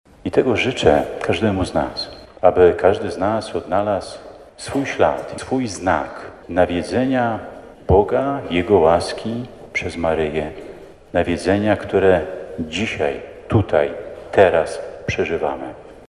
Zawsze budujmy nasze życie na skale którą jest Bóg – zachęcał biskup Marek Solarczyk w czasie nawiedzenia kopii Jasnogórskiego Wizerunku w sanktuarium Matki Bożej Loretańskiej.
Niech Maryja umacnia was swoim błogosławieństwem – życzył wiernym biskup pomocniczy diecezji warszawsko-praskiej przekonując, że spotkanie z Matką Bożą zawsze wnosi dar obecności Boga.